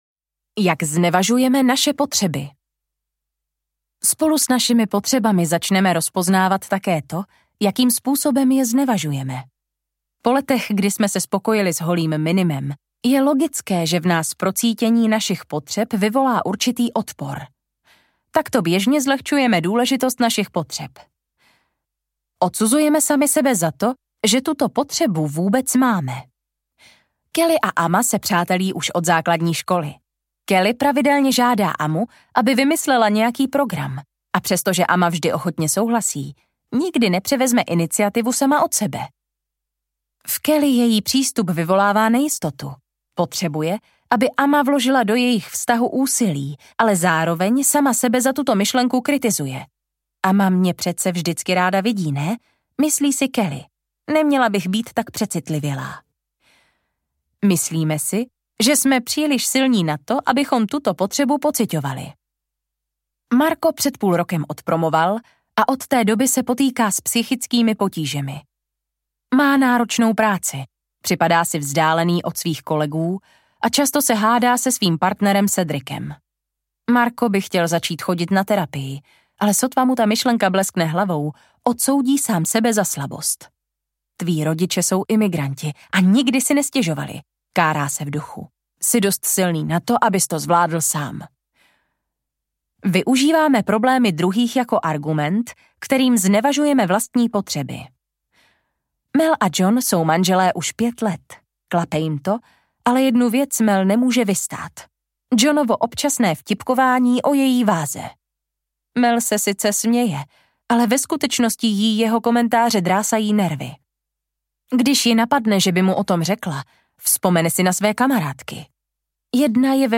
Nebuďte people-pleaser audiokniha
Ukázka z knihy